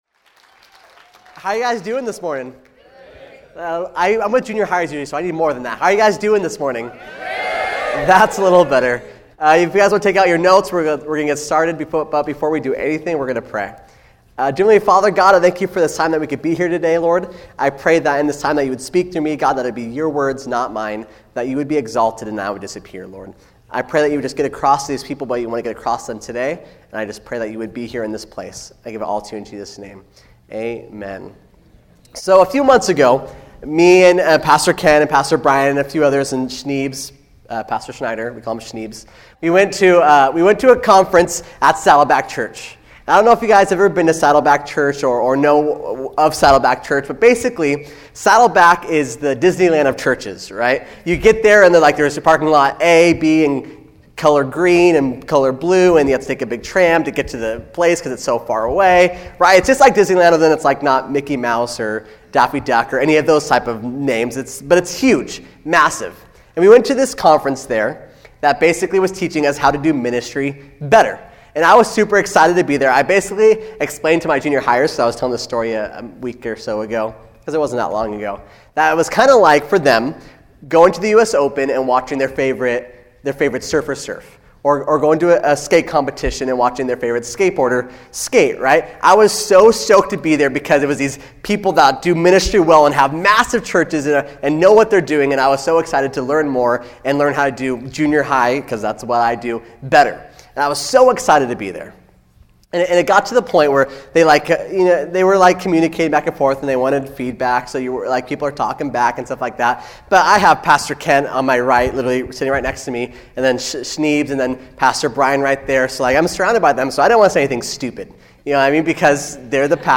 Listen or watch to this convicting message about the significance of Jesus raising from the dead and what that means for us. SERMON AUDIO: SERMON NOTES: